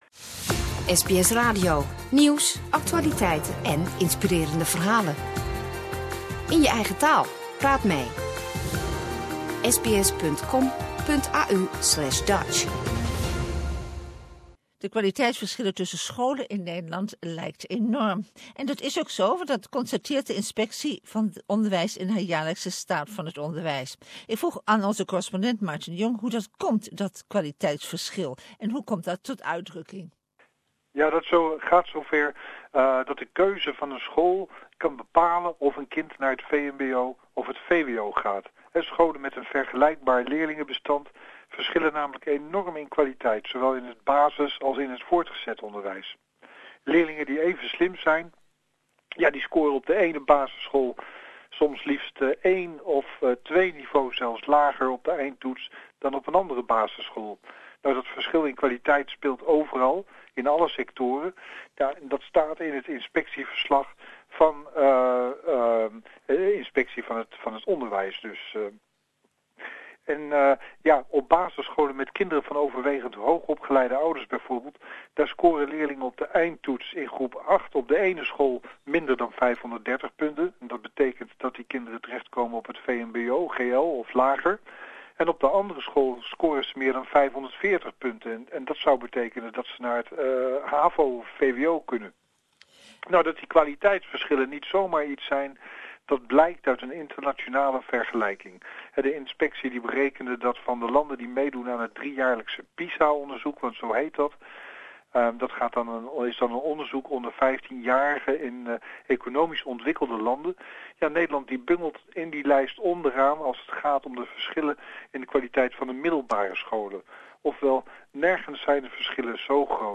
The Dutch educational system is lagging behind says the Dutch education inspection service. Dutch correspondent